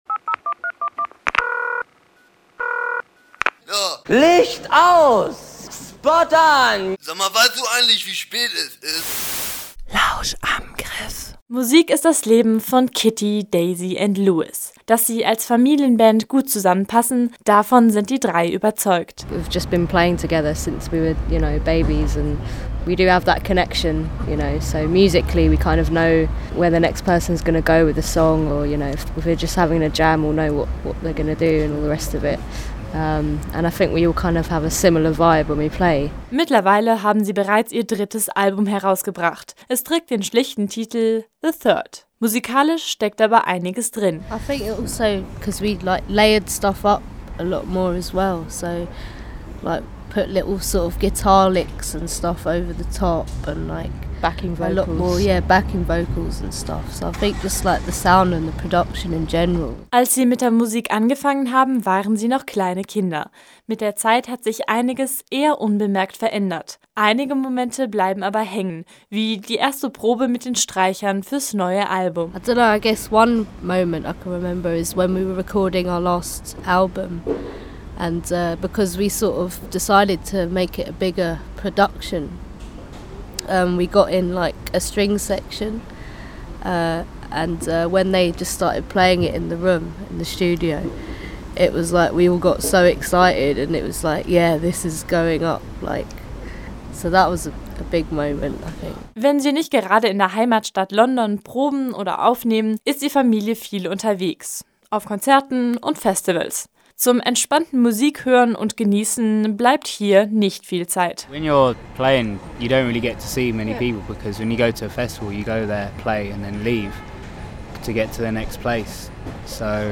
Wir haben sie auf der Kulturarena in Jena getroffen und über Festivals und Konzerte gesprochen.